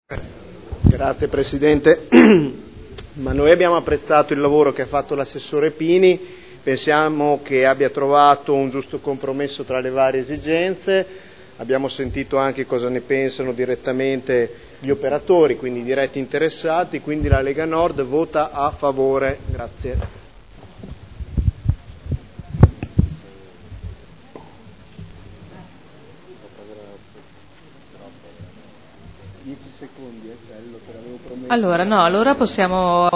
Seduta del 04/04/2011. Dichiarazione di voto su delibera: Servizi di trasporto pubblico non di linea: taxi e noleggio con conducenti di veicoli fino a 9 posti – Approvazione nuovo Regolamento comunale e modifica art. 28 del Regolamento per l’applicazione della tassa per l’occupazione di spazi ed aree pubbliche e per il rilascio delle concessioni di suolo pubblico (Commissione consiliare del 15 e del 29 marzo 2011)